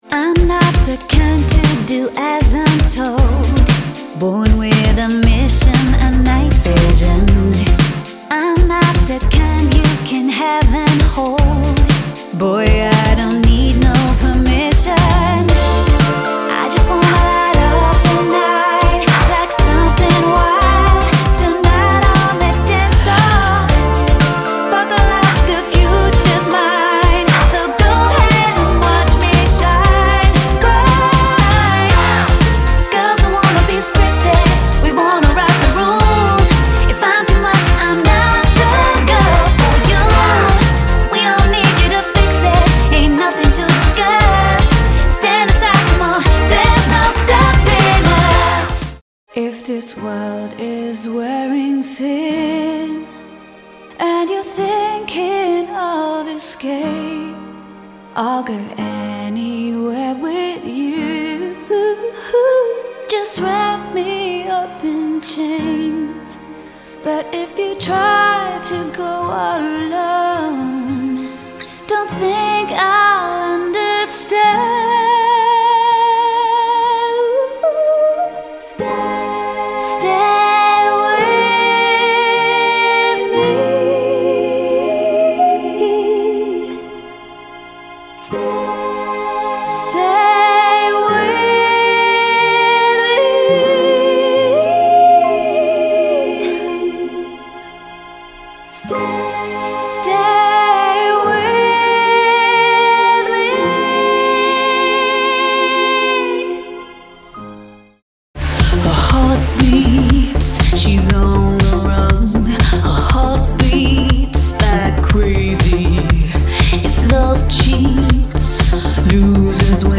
Singing Showreel
Female
Neutral British